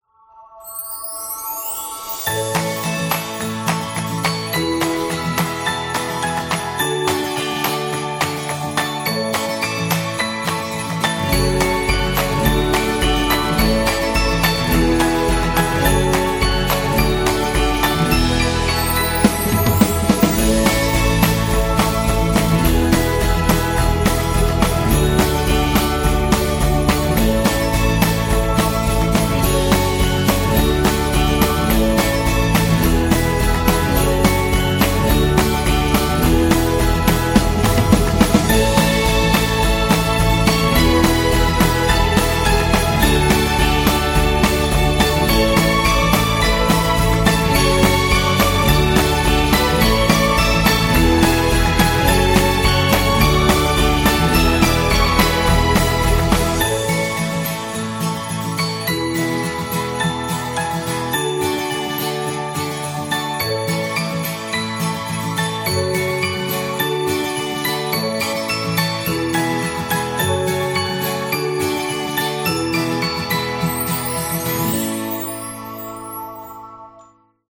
Uplifting, playful, and heartwarming